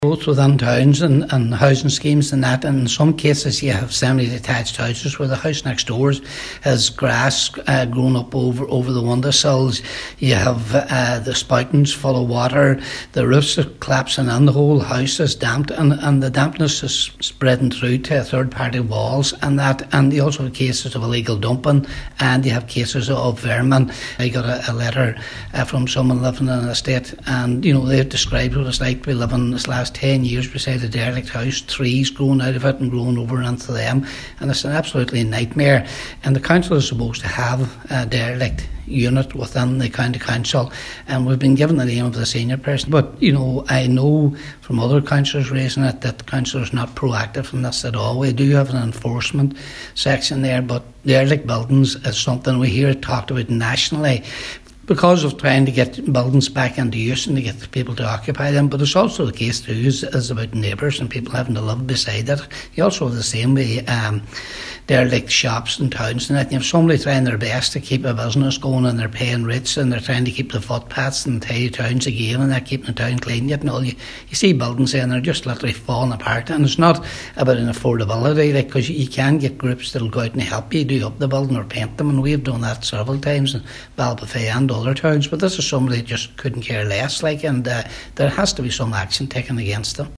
Councillor Patrick McGowan said only last week he was contacted by a local resident who has been living beside an abandoned property now for 10 years.
He says urgent action needs to be taken to clean-up the area: